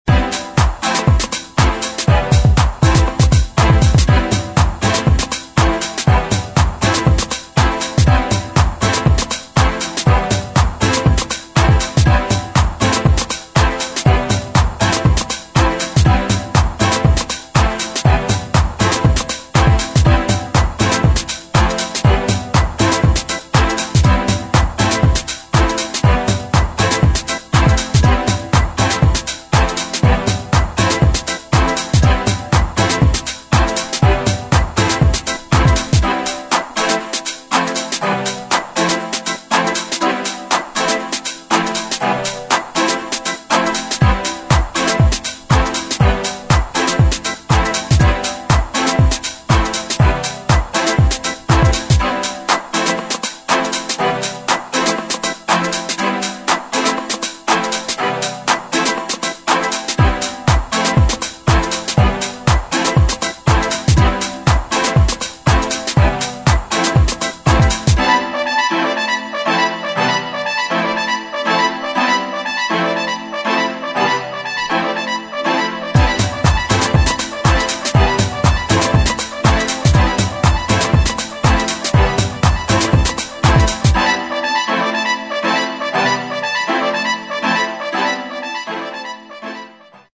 [ HOUSE / DEEP HOUSE ]